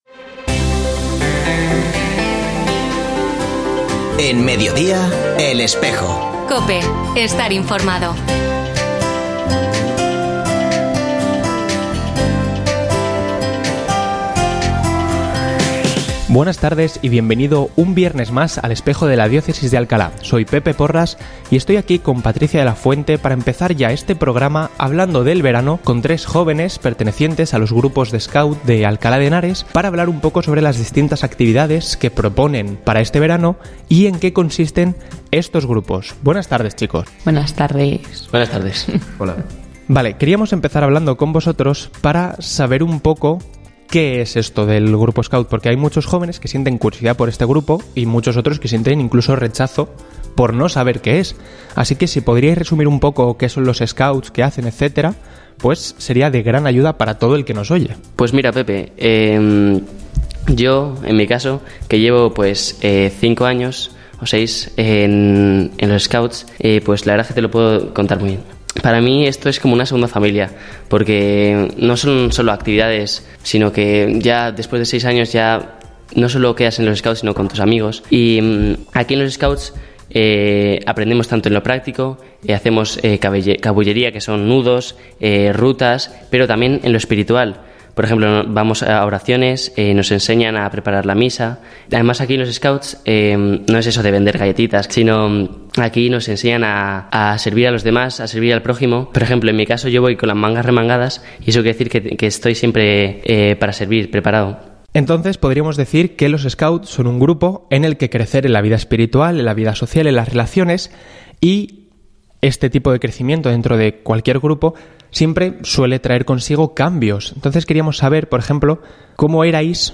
Escucha otras entrevistas de El Espejo de la Diócesis de Alcalá
Ofrecemos el audio del programa de El Espejo de la Diócesis de Alcalá emitido hoy, 21 de junio de 2024, en radio COPE.
Hoy comenzamos conociendo un poco más de fondo a los Scouts de Europa en nuestra diócesis gracias a tres de sus integrantes que se encuentran actualmente discerniendo su servicio como monitores del grupo en un futuro próximo. Dos jóvenes pertenecientes al grupo scout Justo y Pastor y una joven del Grupo Guía Virgen del Val nos cuentan qué ha significado para ellos el grupo, sus planes de verano, el lugar de Dios en el grupo y, cómo no, alguna divertida anécdota.